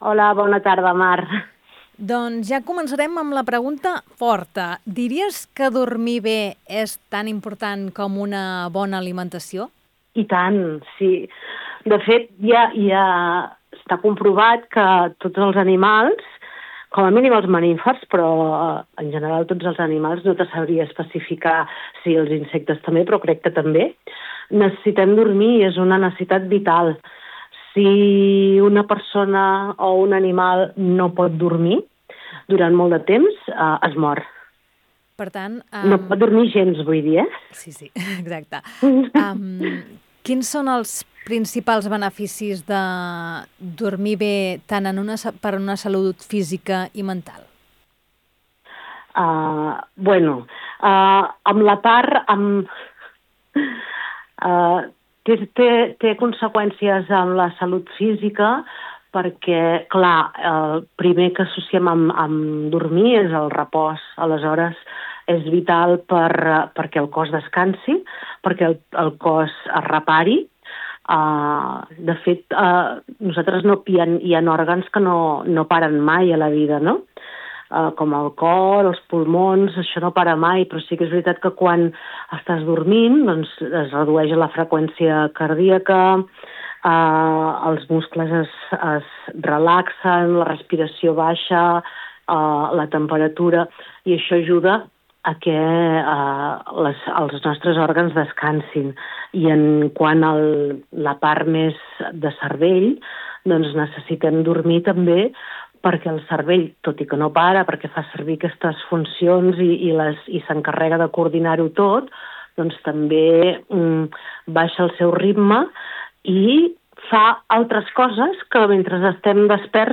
El diàleg flueix entre exemples reals, petites claus pràctiques i una reflexió final: cuidar el descans és cuidar-nos a nosaltres mateixos. Una entrevista que convida a parar, respirar i mirar el nostre propi ritme amb una mica més de consciència.